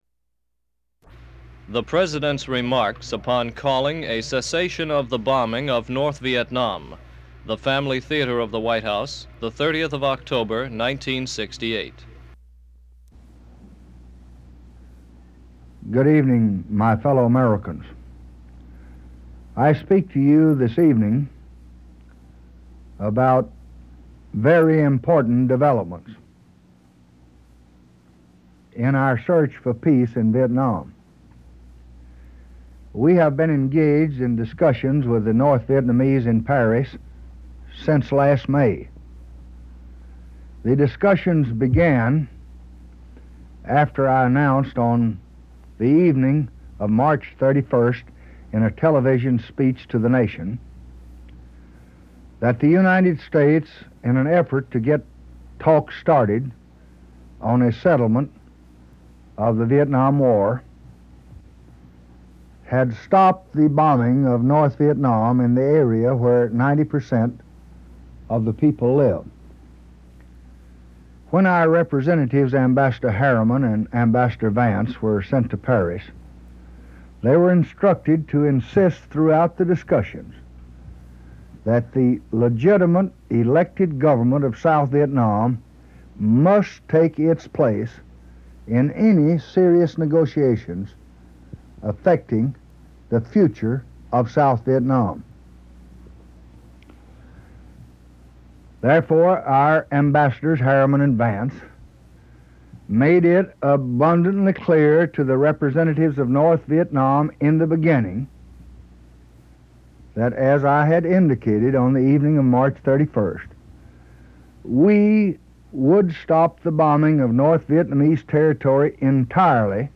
Presidential Speeches | Lyndon B. Johnson Presidency